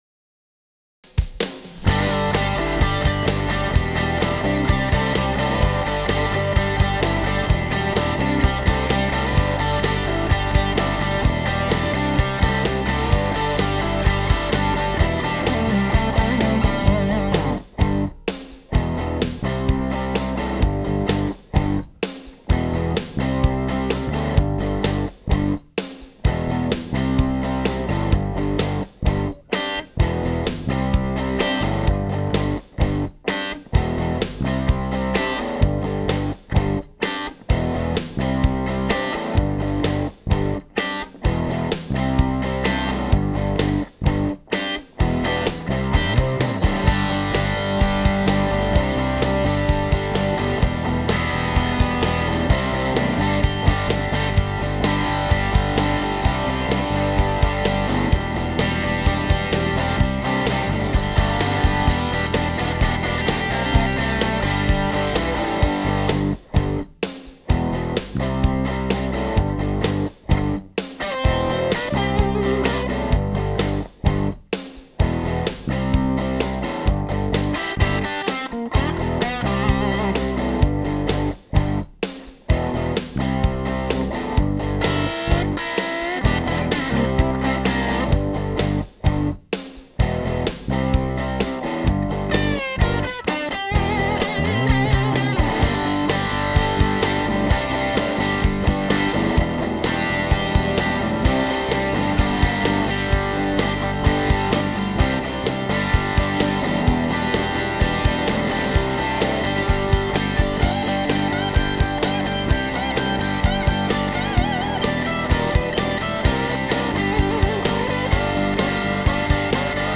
Backwoods-NO VOX.wav